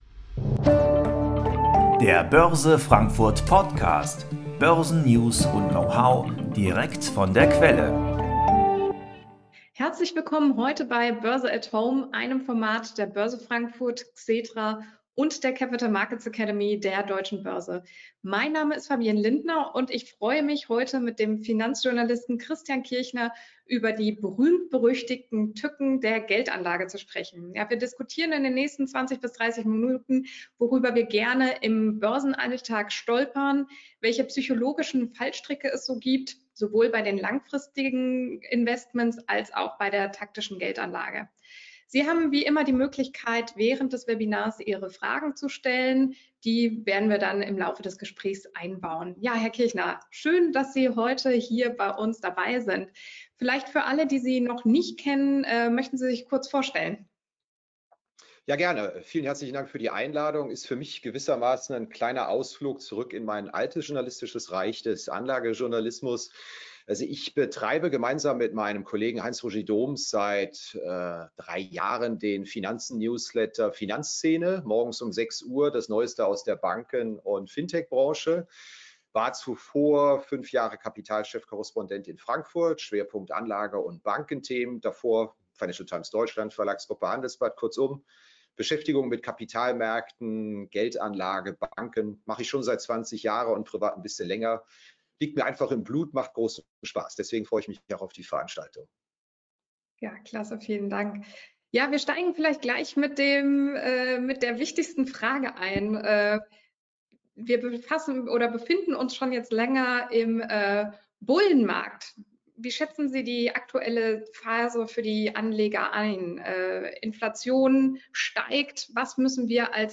Dies ist eine Audiofassung